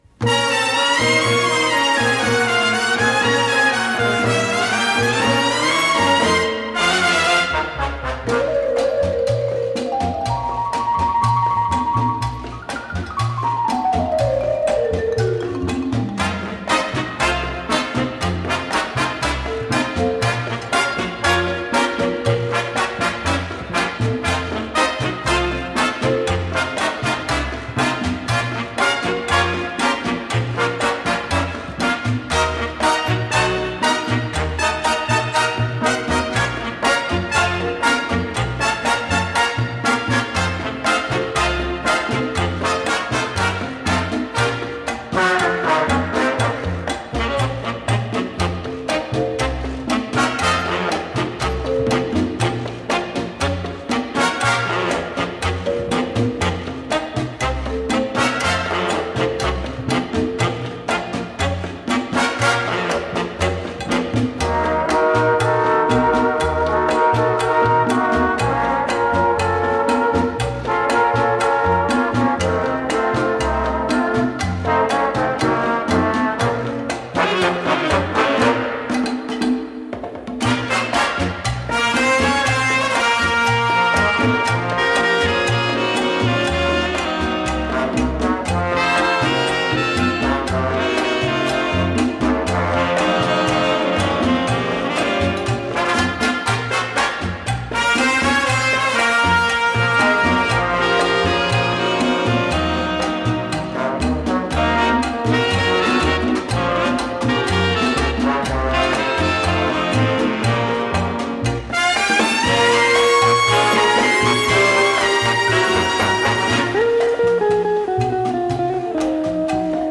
Жанр: Easy Listening, Latin